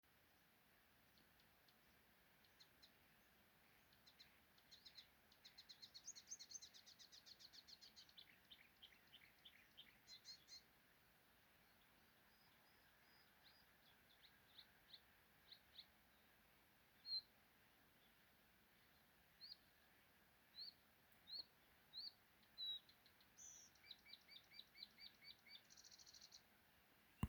болотная камышевка, Acrocephalus palustris
СтатусПоёт